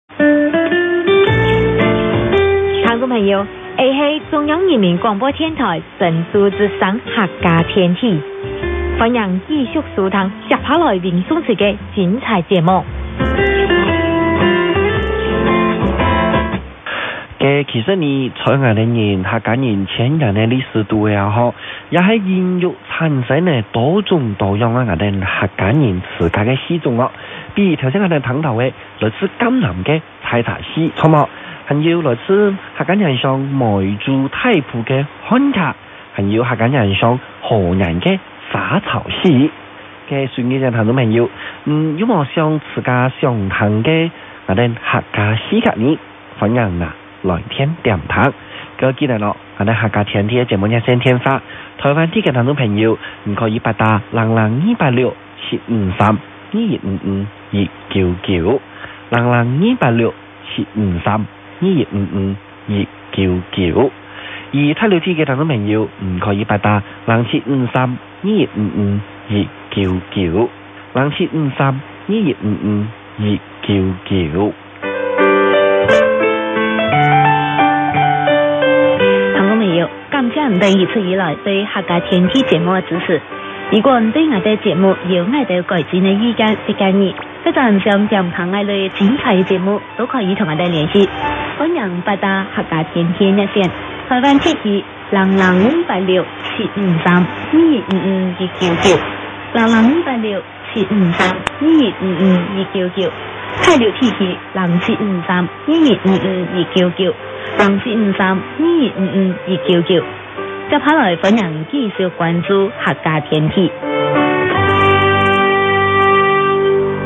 昨日、短波ラジオを聴いていたら音楽を流している局が有ったので調べてみました。 中央人 民広播電台第６放送で、客家語番組とのことです。 客家語がどんなものかを知る良い機会と思っ て聴いてみることにした途端、 客家語のアナウンス が期待以上に明瞭に飛び込んできました。